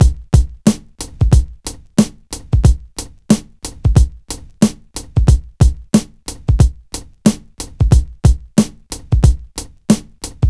• 91 Bpm Breakbeat F Key.wav
Free drum loop sample - kick tuned to the F note.
91-bpm-breakbeat-f-key-Fny.wav